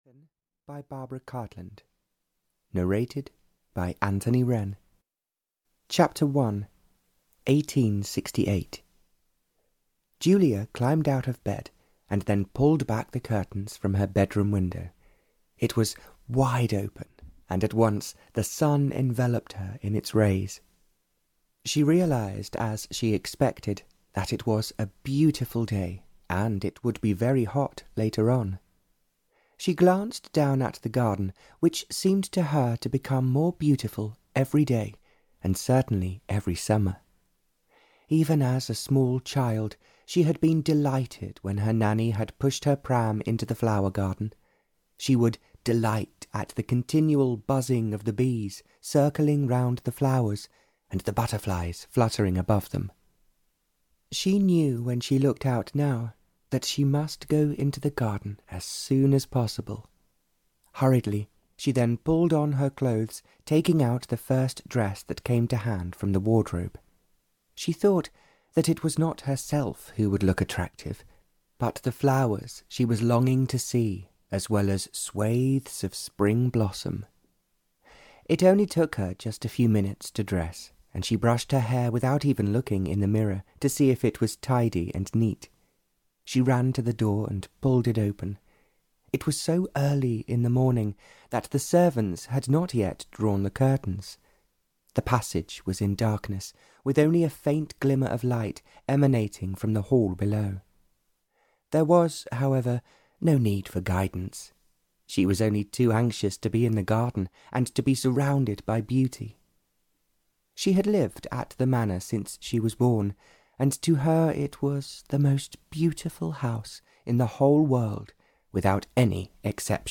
For the Love of Scotland (Barbara Cartland's Pink Collection 140) (EN) audiokniha
Ukázka z knihy